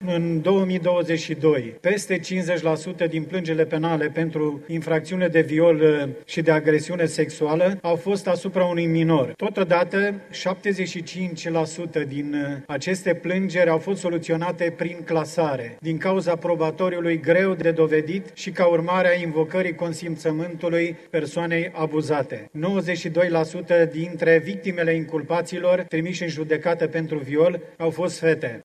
Deputatul social-democrat, Viorel Sălan, consideră că noile reglementări sporesc gradul de siguranţă a minorului care merge în străinătate: